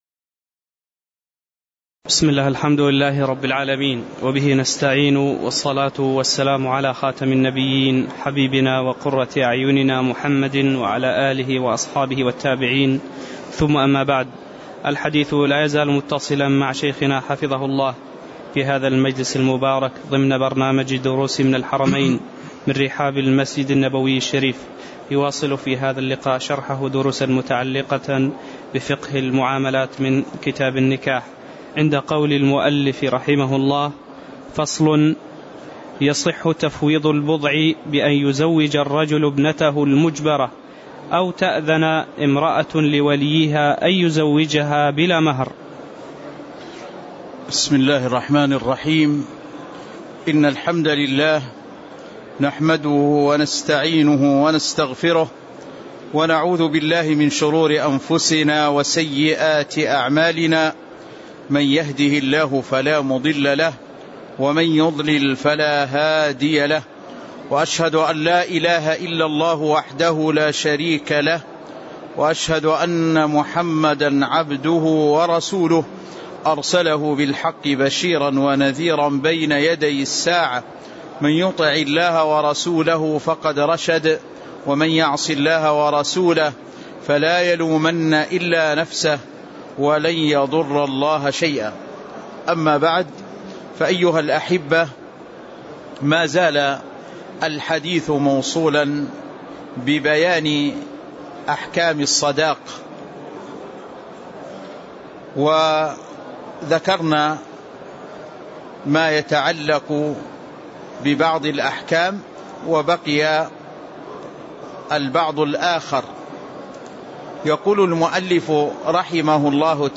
تاريخ النشر ٢١ جمادى الأولى ١٤٣٧ هـ المكان: المسجد النبوي الشيخ